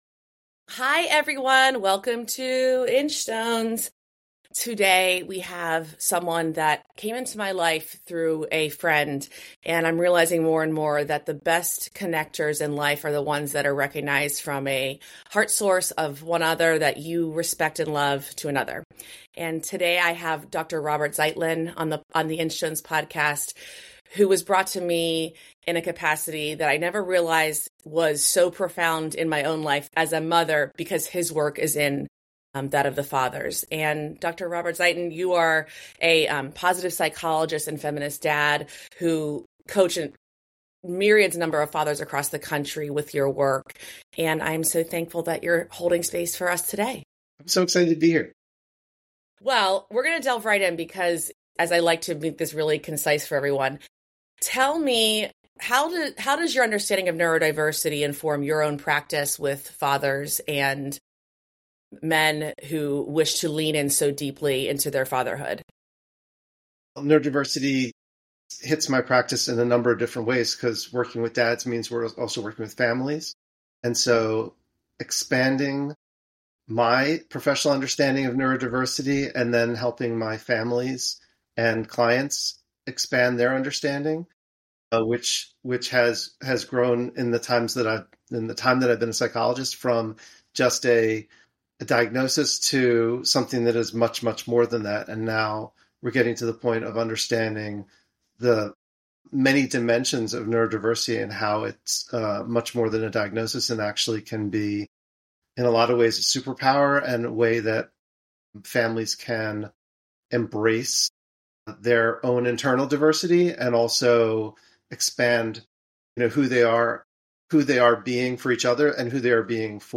This conversation highlights the importance of connection, vulnerability, and support for fathers, especially those raising neurodivergent kids.